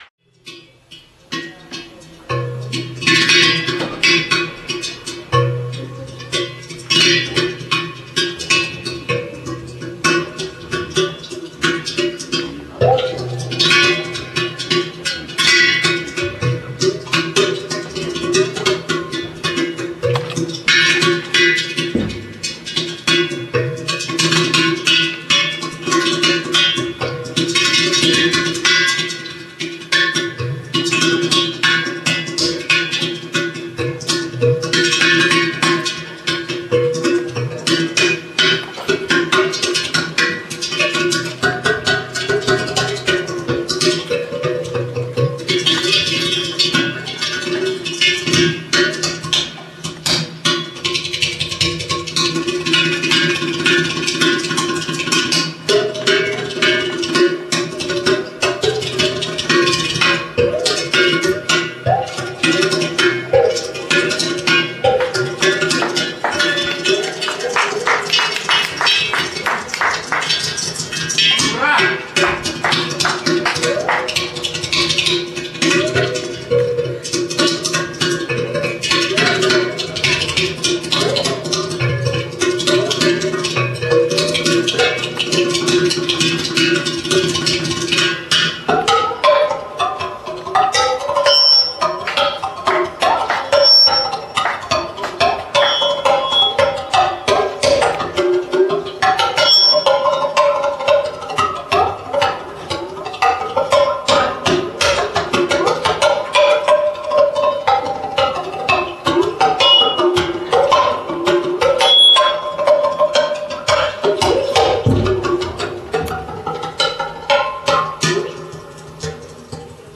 Teapot With Water, Dan Moi, Ghost Catcher.
TeapotWithWater.rm